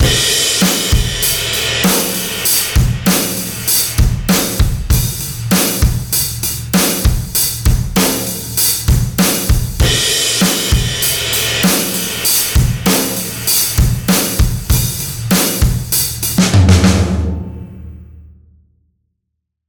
T-RackS Saturator X is a multi-mode harmonic saturation processor. It delivers that classic analog saturation and opens your recordings to the mysterious, elusive and warm world of analog saturation.
Saturator_X_Pumping_Drums.mp3